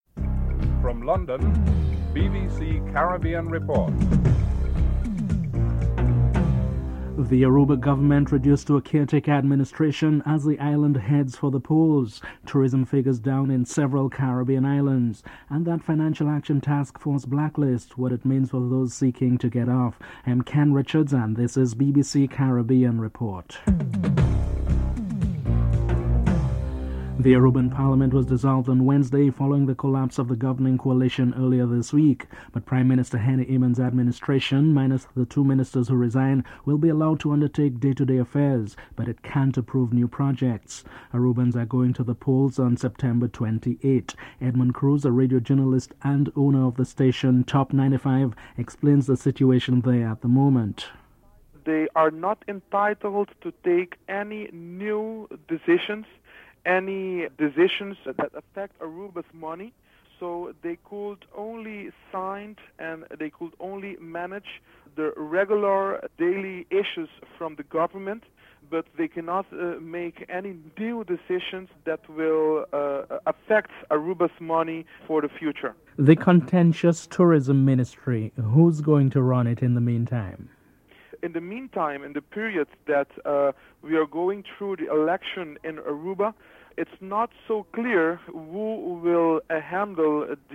1. Headlines (00:00-00:26)
Prime Minister Ralph Gonsalves is interviewed (08:05-11:05)
Finance Minister Sir William Allen is interviewed (13:03-15:31)